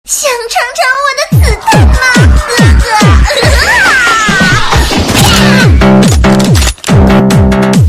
SFX想尝尝我的子弹吗，biu音效下载
SFX音效